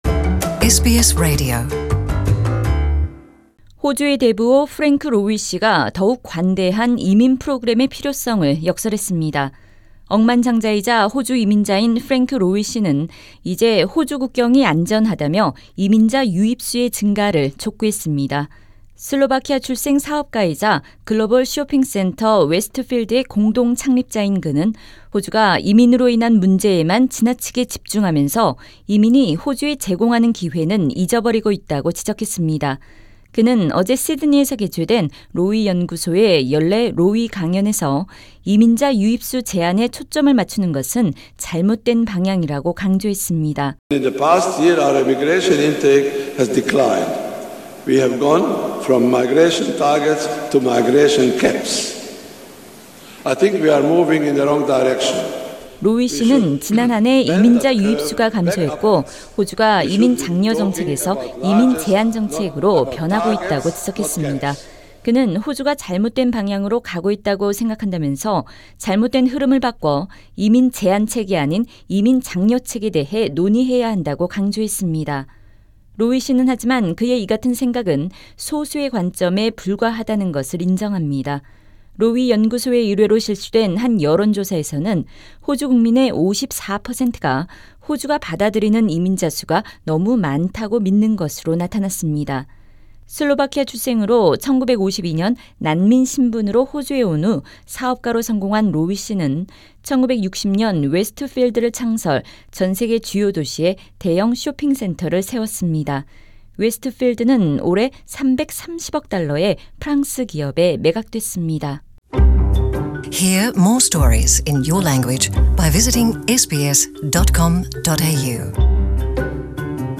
Sir Frank Lowy delivers the annual Lowy Institute's lecture in Sydney.